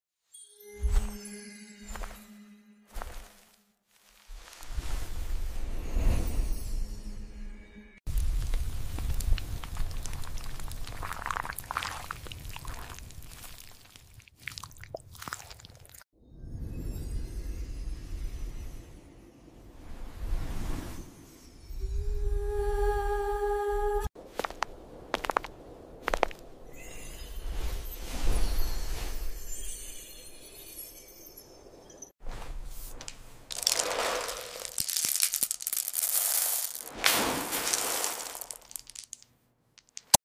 🌙💤 From crystal veggies to pizza, the sun, M&Ms and more… these ASMR fantasy beds are too surreal to resist.